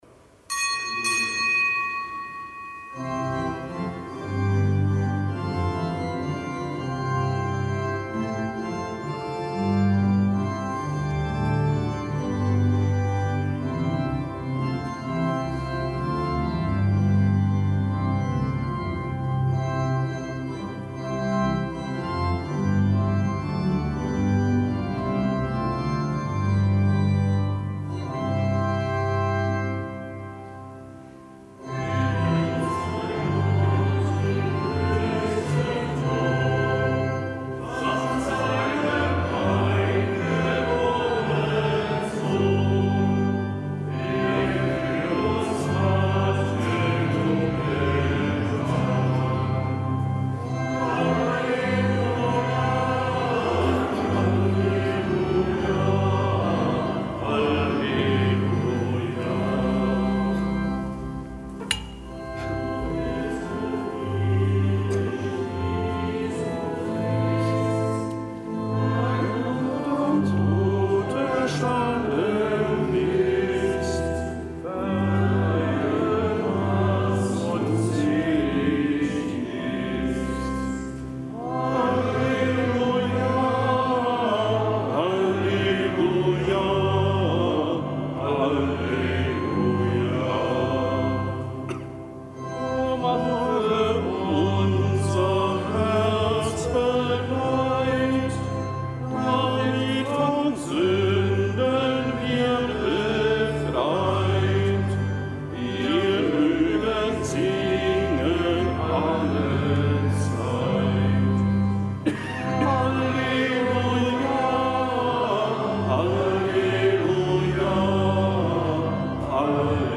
Kapitelsmesse aus dem Kölner Dom am Samstag der Osteroktav.